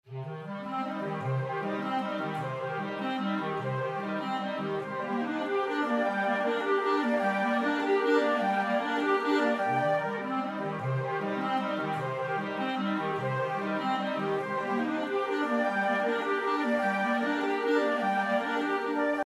Klarinetten- und Flöten-Arpeggios zeitversetzt:
(O.k., da habe ich ein wenig geschummelt: Im Überlappungsbereich der Grundtonwechsel kommt es zu Disharmonien, da die Arpeggien nicht in einer gemeinsamen Tonart spielen.